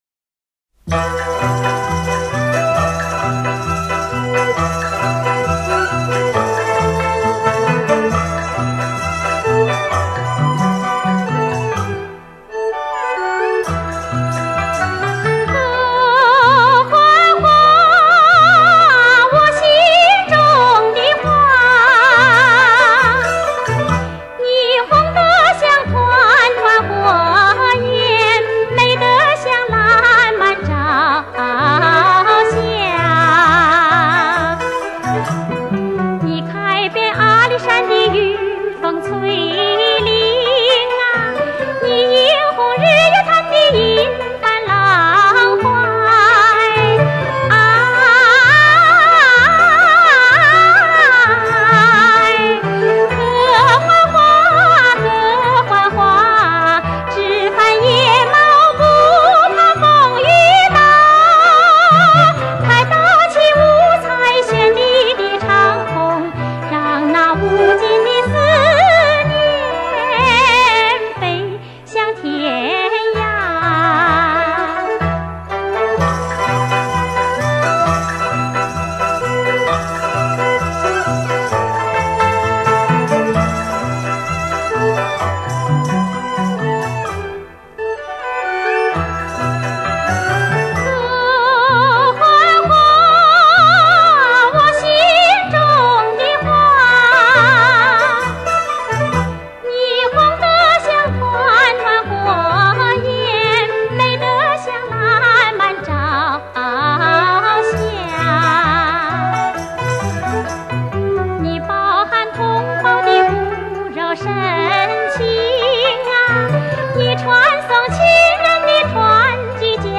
其演唱声从情发，委婉甜美，格调清新秀丽，深受广大群众喜爱